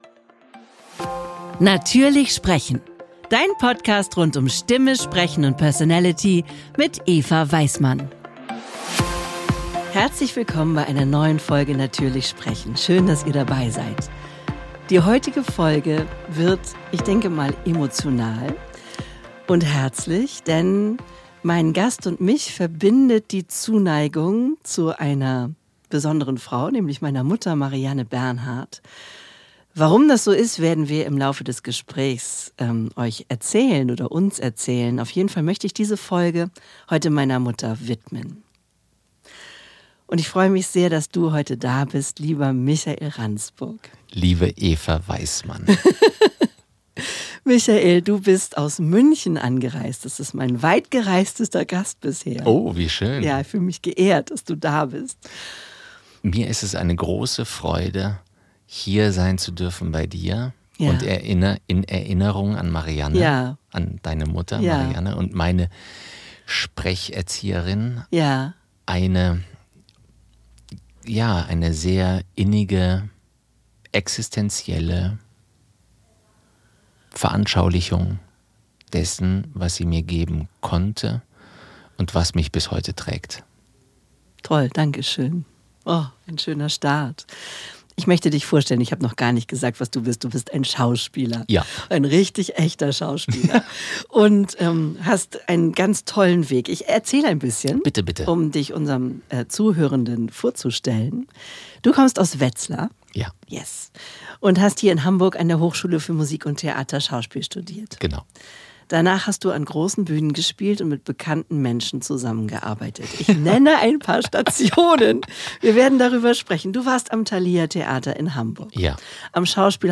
Ein philosophisches Gespräch mit dem Schauspieler